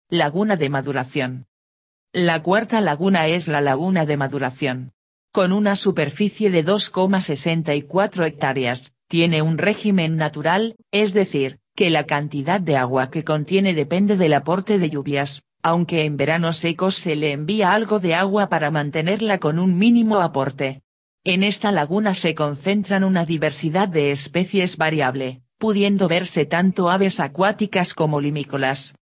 Locucion: